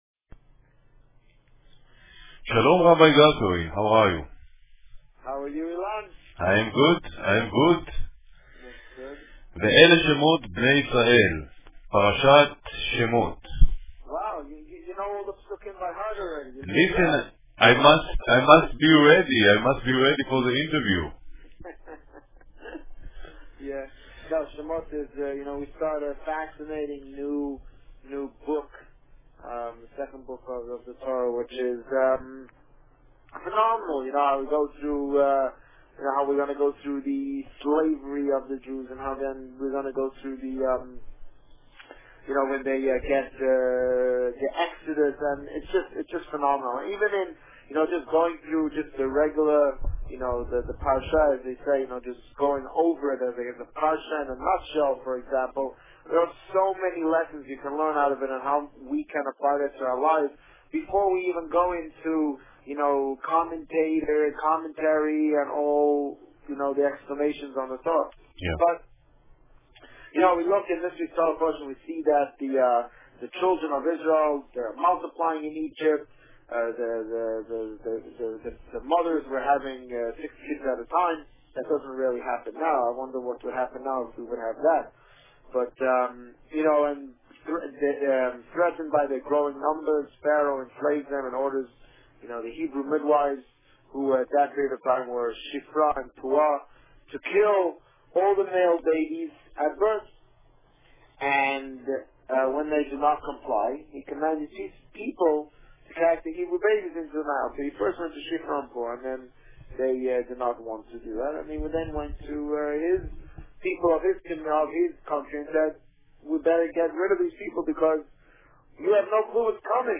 Warning: Because this interview was not recorded in the radio station, the usual audio quality was sub-par. I have tried to enhance it to the point where it can be understood, but there may be places where the volume may be too loud.